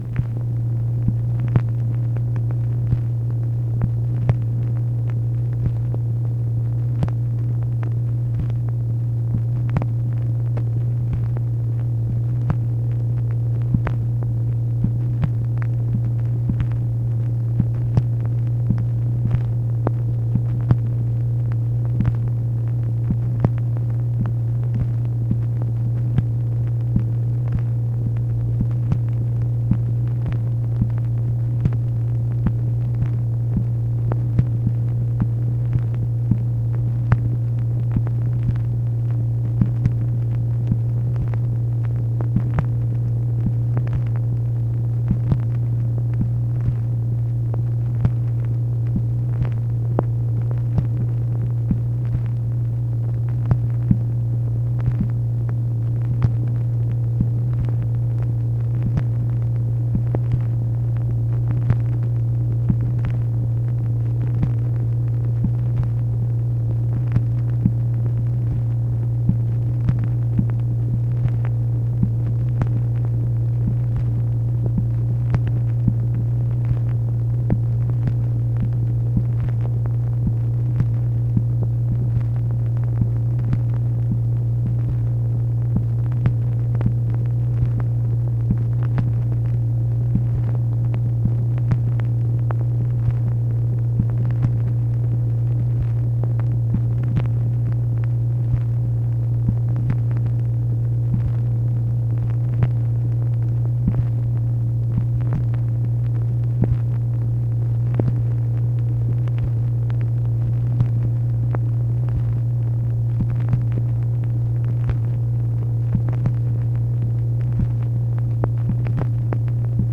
MACHINE NOISE, February 7, 1964
Secret White House Tapes | Lyndon B. Johnson Presidency